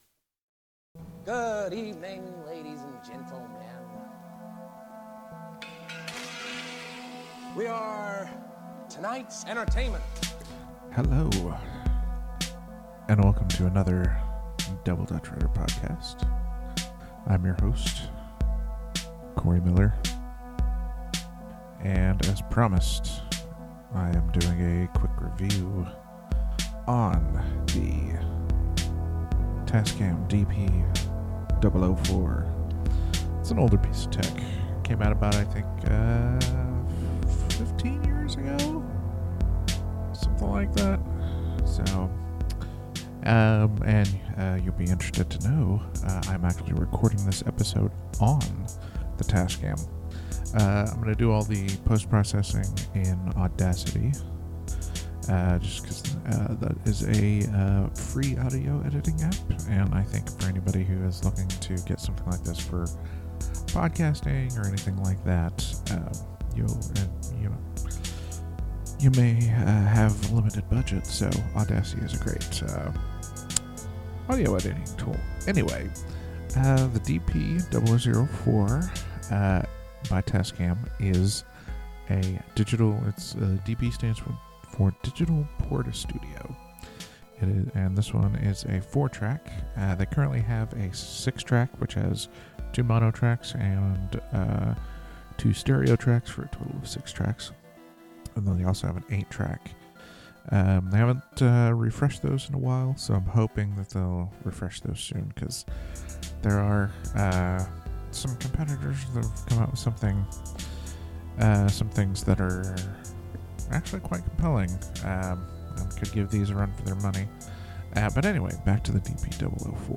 A quick review of the old digital portastudio by Tascam, featuring the Tascam DP-004 itself!